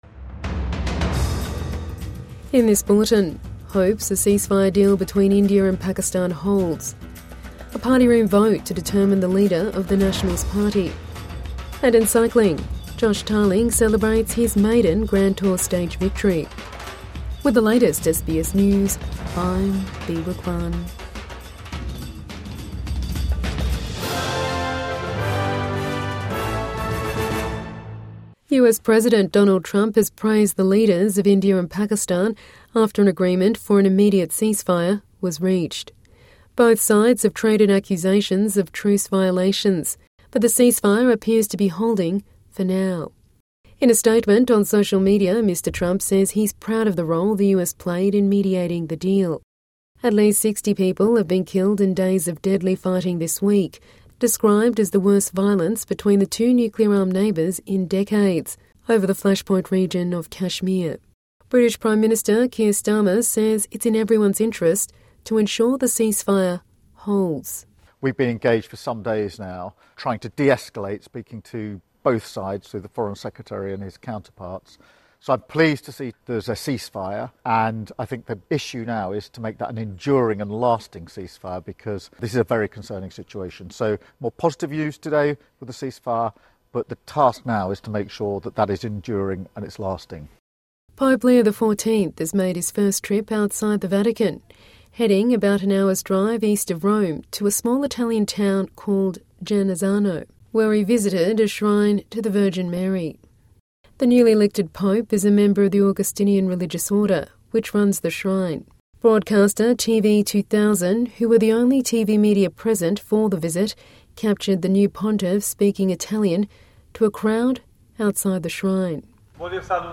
Evening News Bulletin 11 May 2025